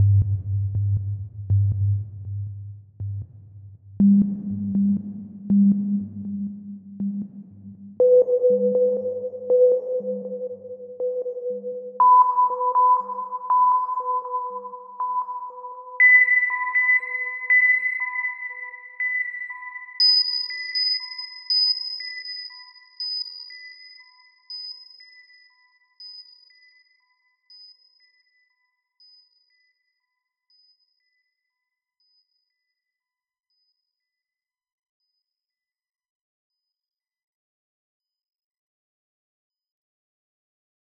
Atlas - STest1-PitchPulse-100,200,500,1000,2000,5000.wav